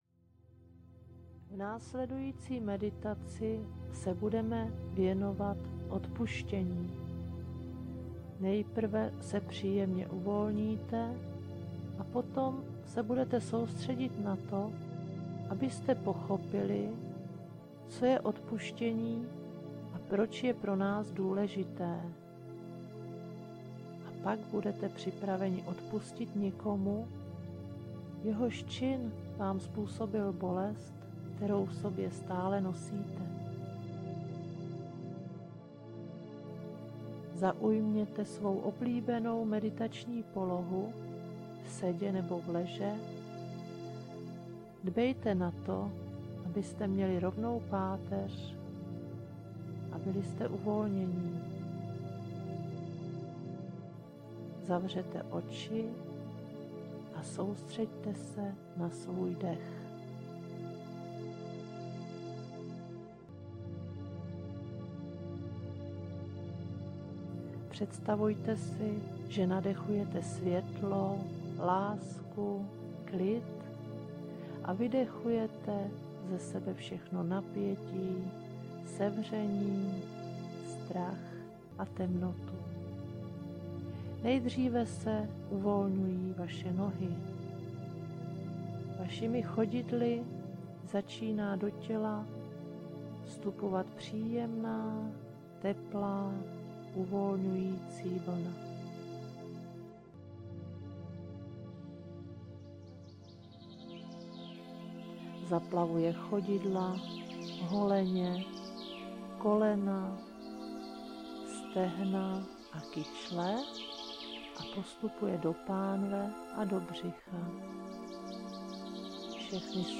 Ukázka z knihy
K vyjádření negativních pocitů a ublížení je dán prostor v úseku výraznější hudby bez mluveného slova.